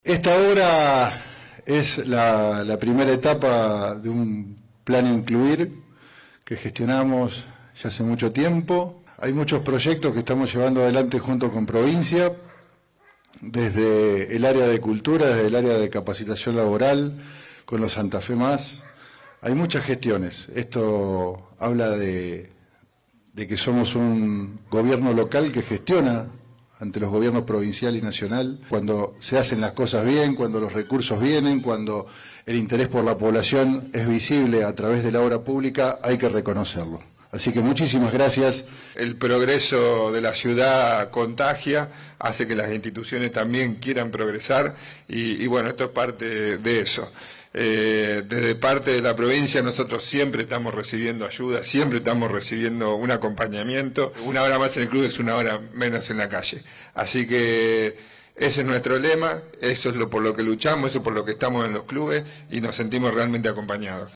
El gobernador participó de la inauguración de obras contempladas en el Plan Incluir en Las Rosas, que requirieron una inversión de $11 millones.